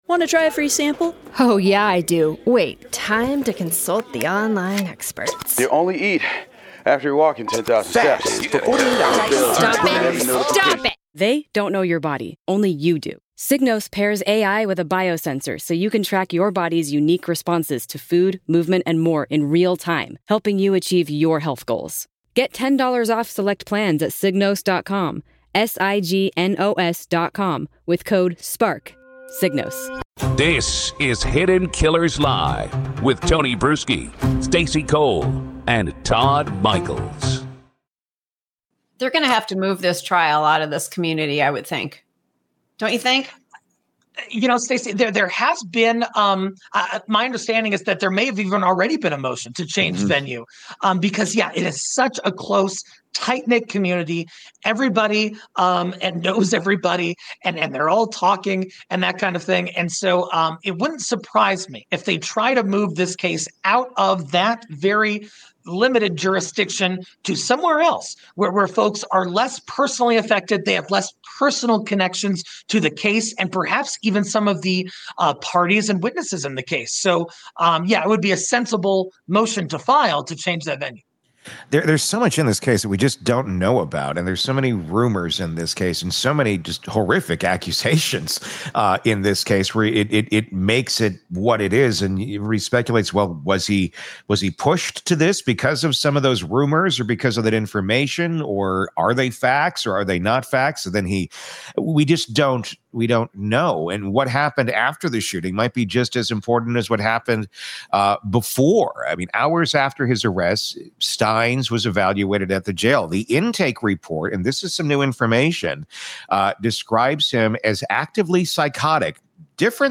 The panel debates the steep uphill battle of an insanity defense, especially with video showing Stines acting methodically in the moments before pulling the trigger. They also analyze the difficulty of investigating corruption in a small community where silence, retaliation, and rumor rule.